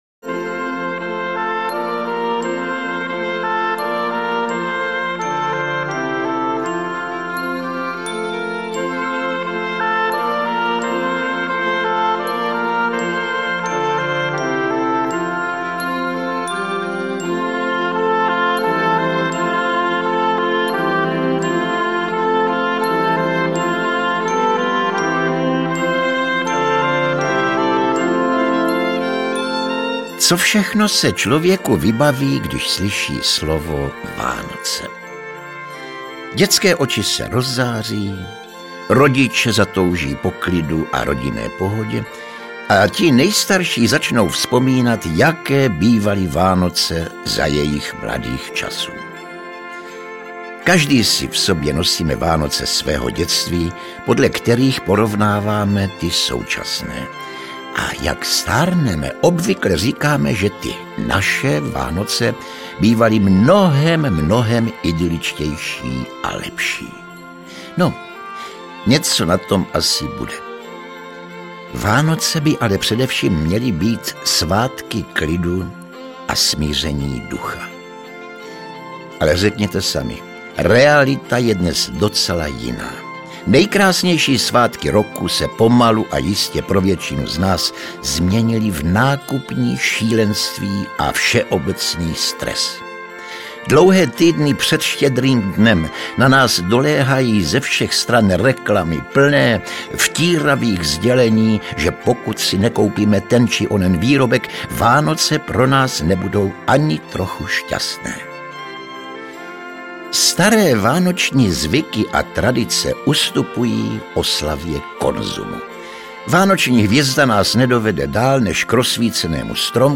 Vánoční povídky a vyprávění audiokniha
Ukázka z knihy
• InterpretJosef Somr, Ladislav Mrkvička, Libuše Šafránková, Rudolf Hrušínský, Dana Syslová